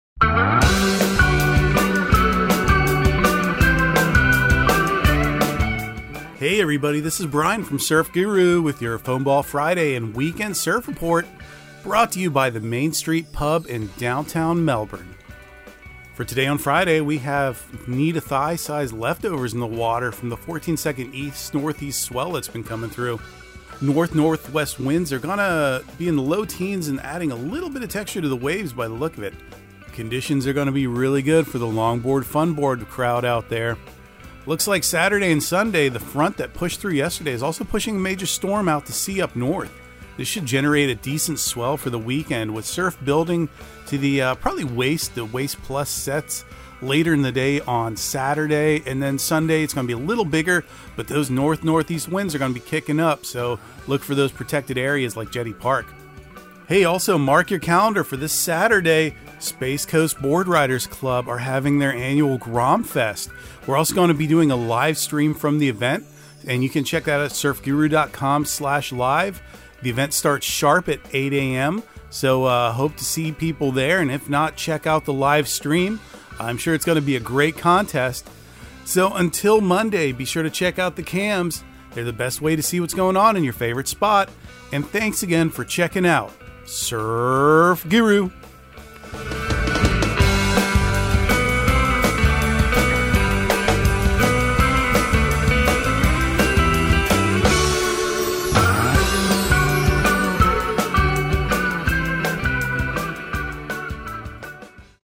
Surf Guru Surf Report and Forecast 12/16/2022 Audio surf report and surf forecast on December 16 for Central Florida and the Southeast.